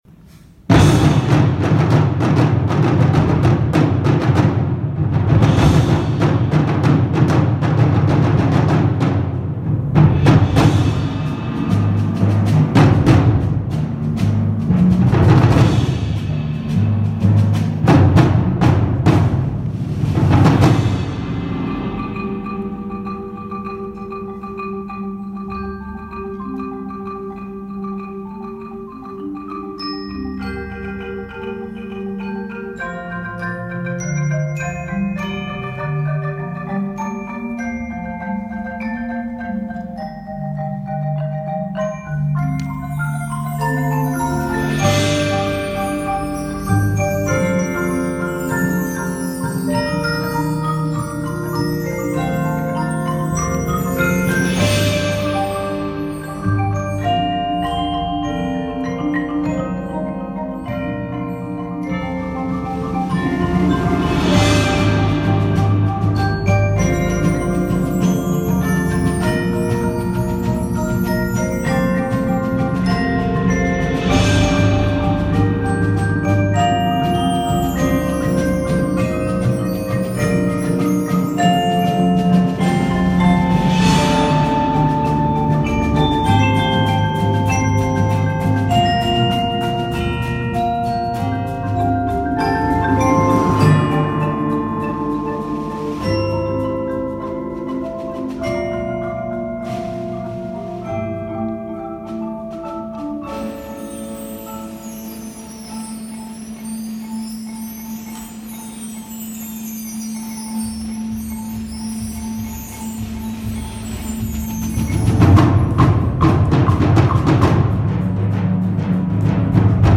Genre: Percussion Ensemble
Player 1: Glockenspiel
Player 2: Chimes
Player 3: Xylophone, Woodblock
Player 4: Vibraphone, Medium Tom
Player 6: Marimba (4-octave), Medium Tom
Player 8: Timpani (4 drums)
Player 9: Snare Drum
Player 10: Bass Drum
Player 11: Large Tam-Tam, Wind Chimes
Player 12: Suspended Cymbal, Shaker/Shekere